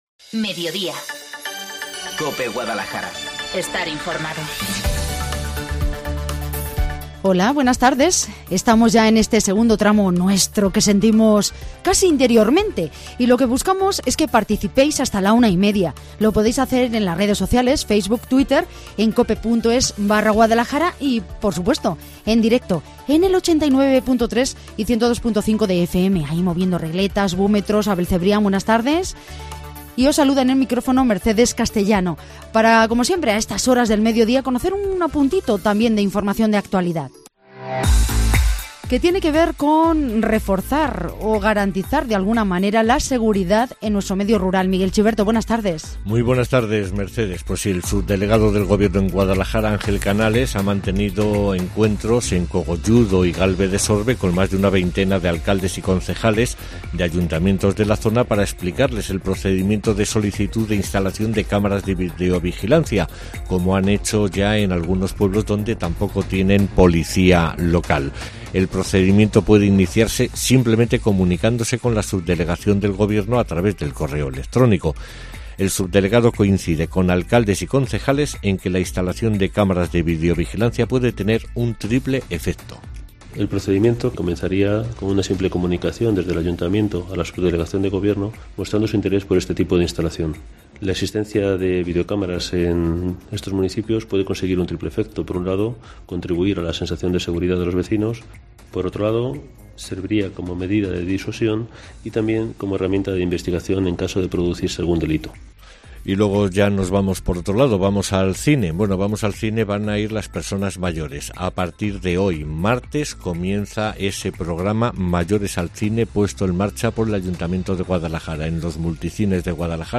AUDIO: Entre 13:20 y 13:30, un médico nos da consejos y recetas para componer menús navideños saludables